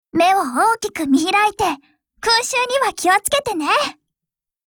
Cv-30125_warcry.mp3